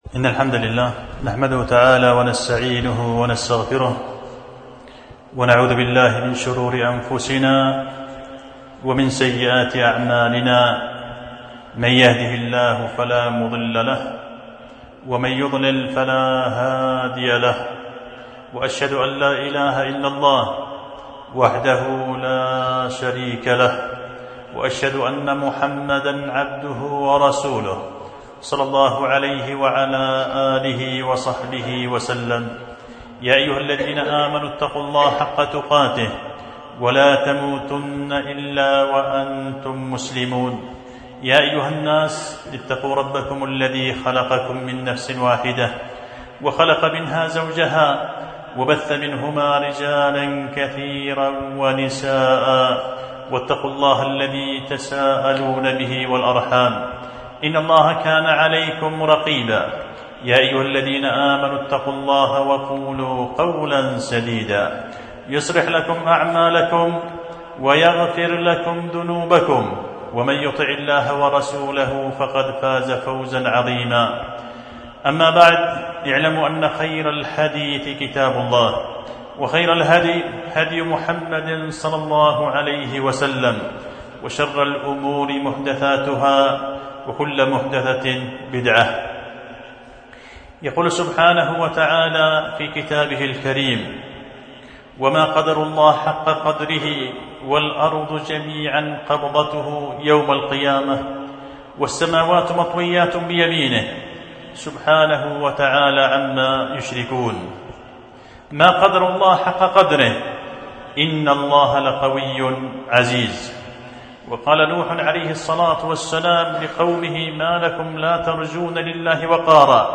خطبة جمعة بعنوان إقامة الدليل على إنكار بعض الصور الخاطئة في المحبة والتبجيل